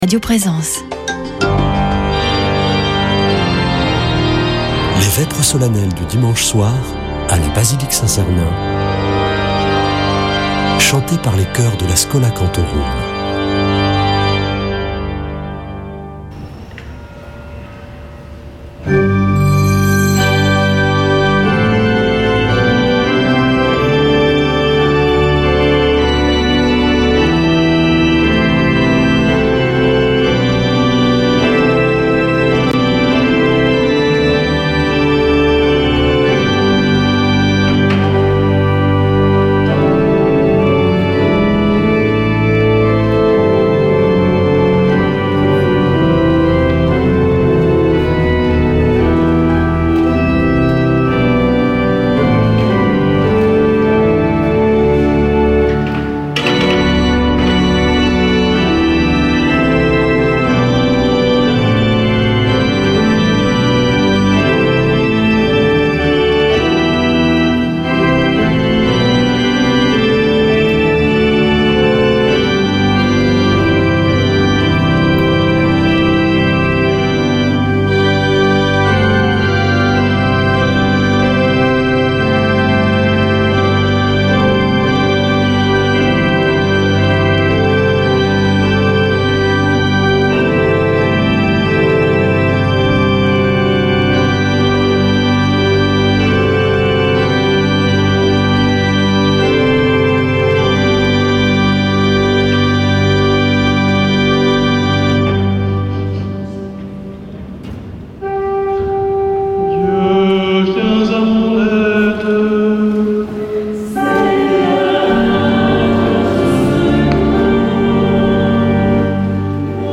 Vêpres de Saint Sernin du 18 juin
Une émission présentée par Schola Saint Sernin Chanteurs